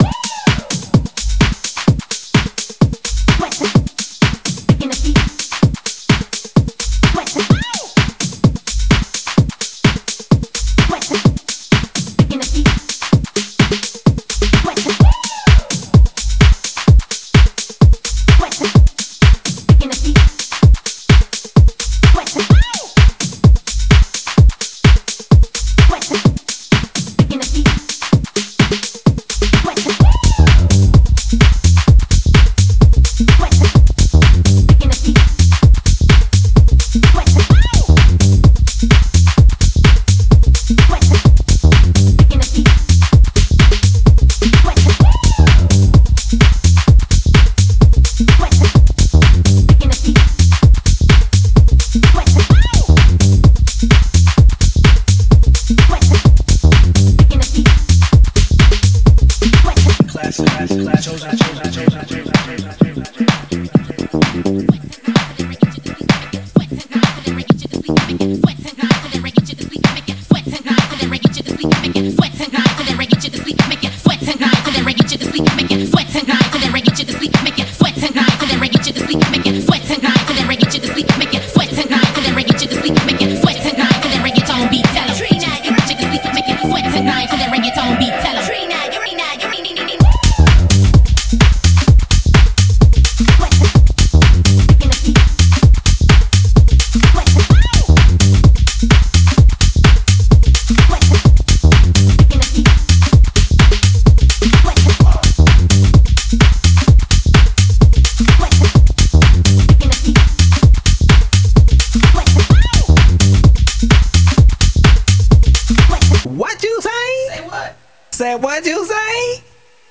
energía vibrante